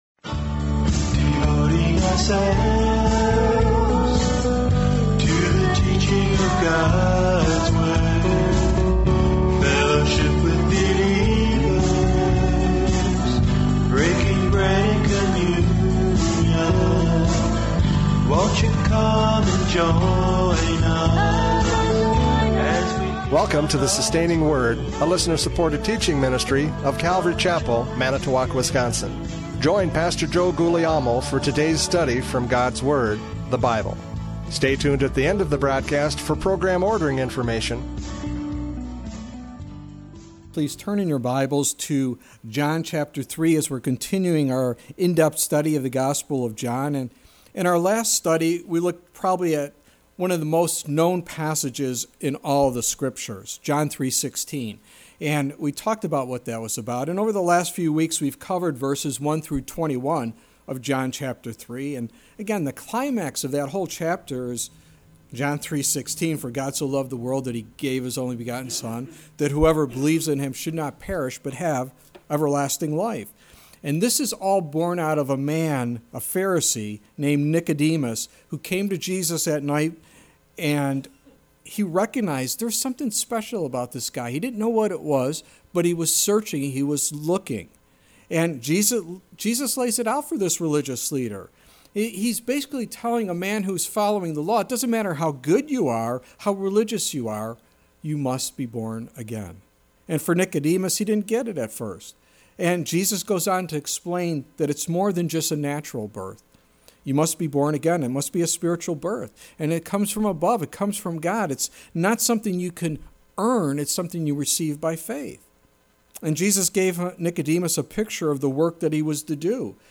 John 3:22-36 Service Type: Radio Programs « John 3:13-21 God’s Love For Us!